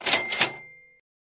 На этой странице собраны реалистичные звуки оплаты банковской картой.
Звук сканирования товара через штрих код